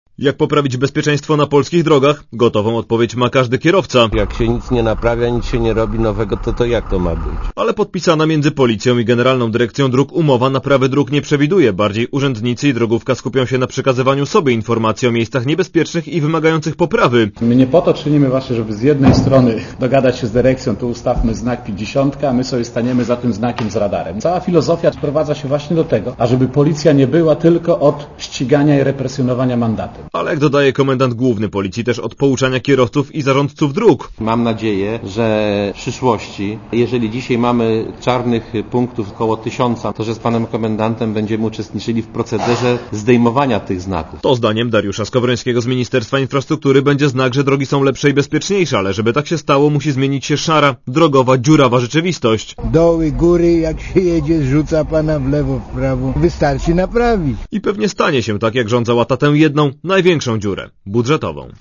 Komentarz audio (240Kb)